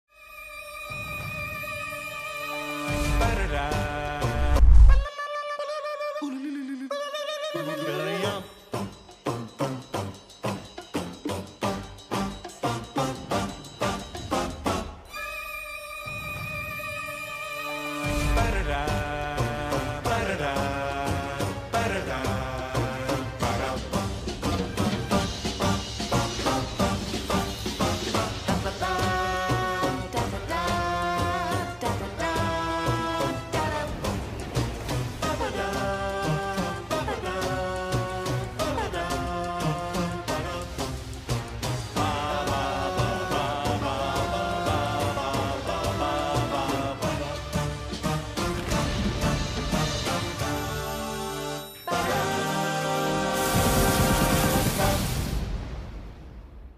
a interprété le générique de la saga